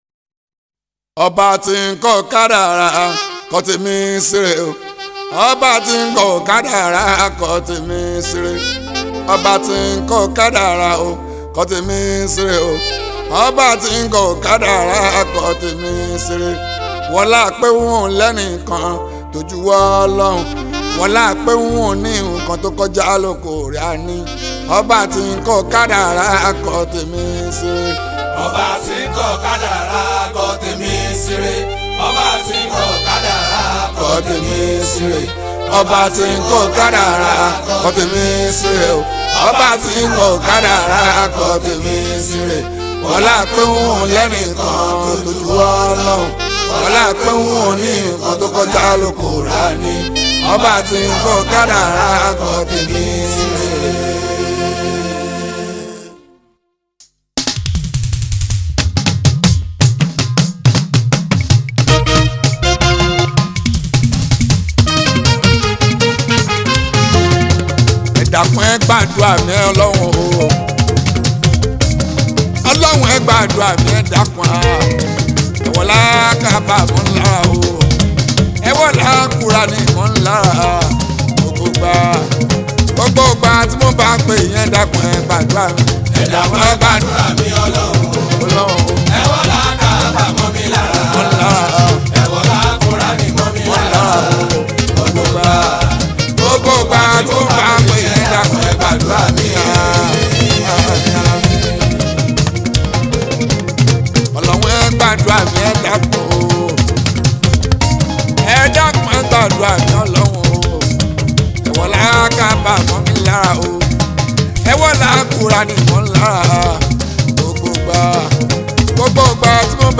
especially people with so much love for Yoruba Fuji Music.